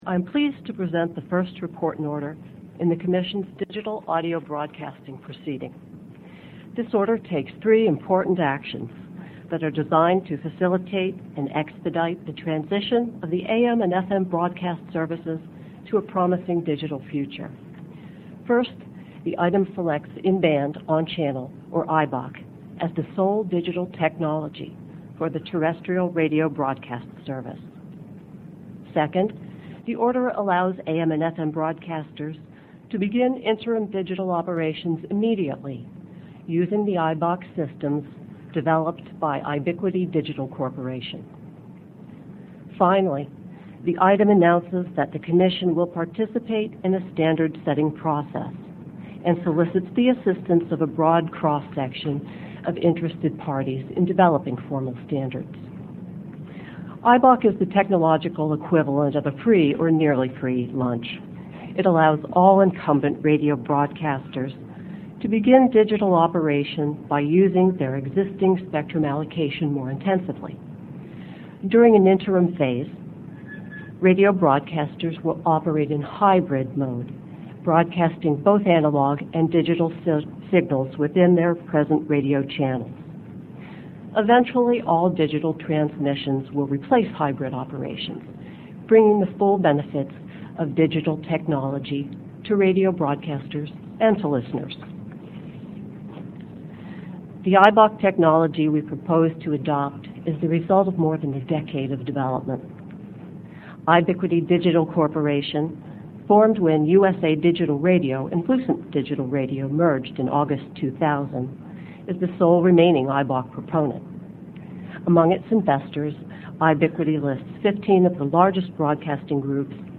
Note: All audio is in 48kbps/44KHz mono MP3 format.